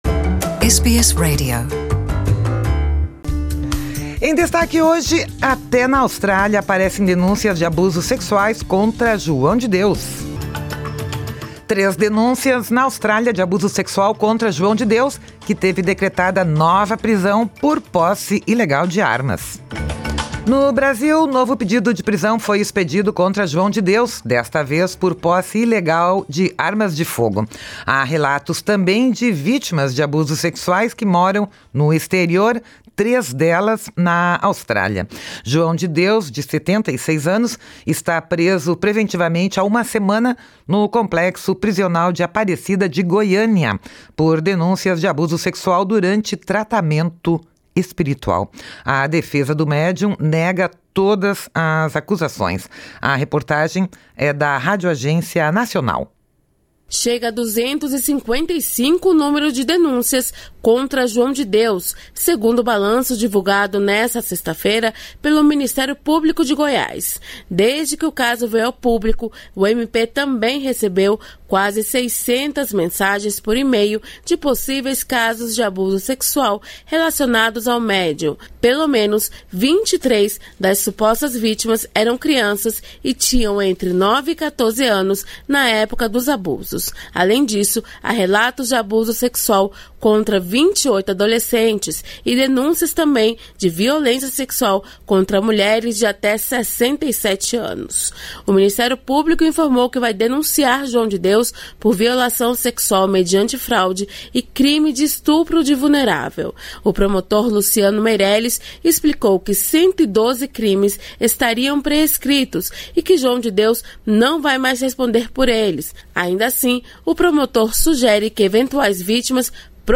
A reportagem é da Rádioagência Nacional.